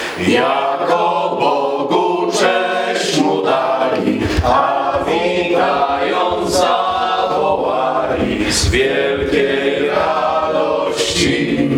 To już piękna świąteczna tradycja – wigilia w Radiu 5 Ełk.
Była okazja do wspomnień, refleksji, życzeń, wspólnego śpiewania kolęd.
op-kolęda-g.mp3